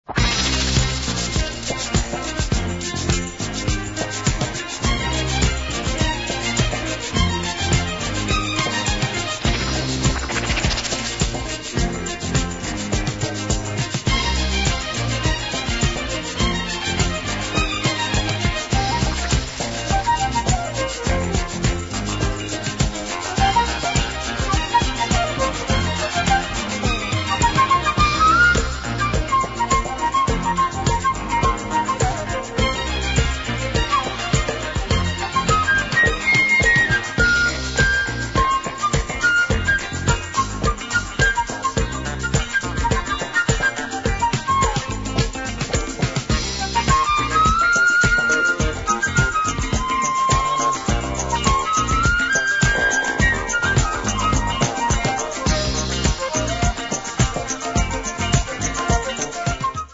Top-drawer flute funk
Weird analogue synth adds to the 'horror' feel of the score.